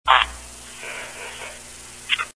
An echoing fart done last semester at the beginning of Spanish class, no one was in the room but it sure was funny because of the echo.[/ur]
fartechos.mp3